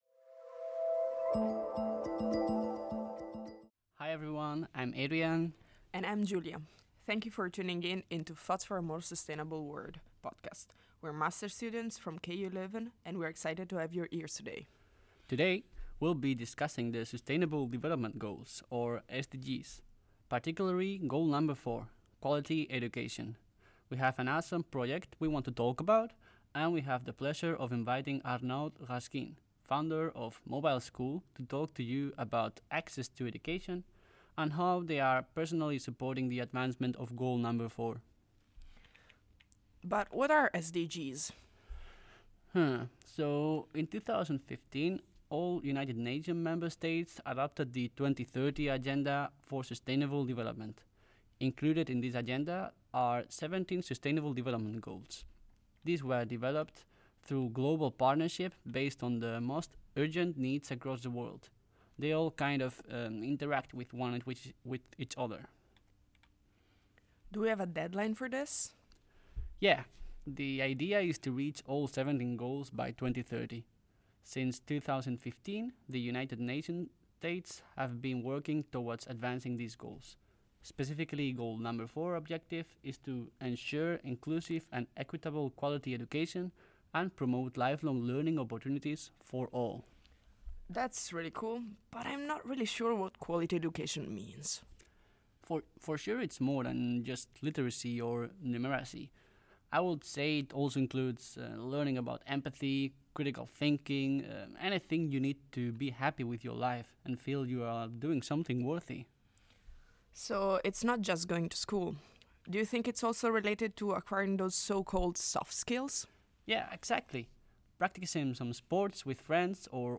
Podcast Production: Overall, our team worked collaboratively, with each member initially taking responsibility for a specific area.